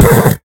sounds / mob / horse / hit4.ogg